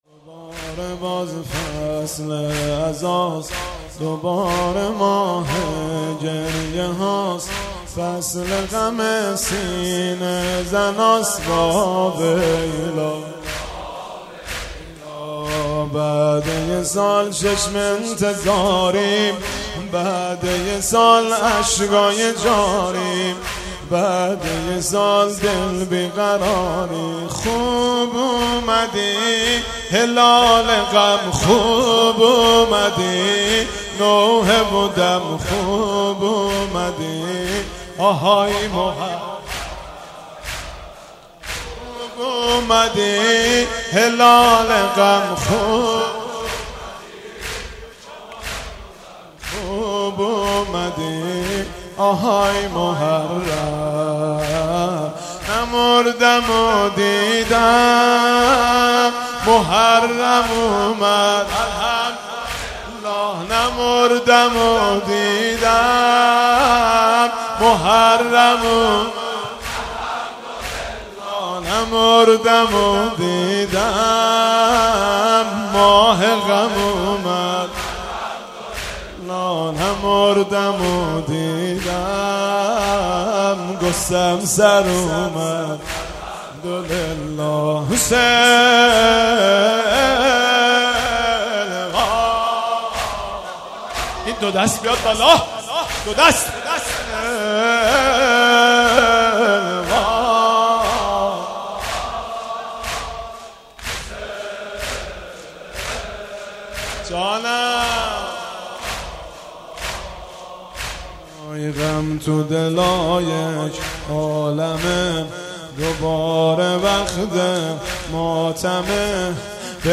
مداحی دوباره باز فصل عزاست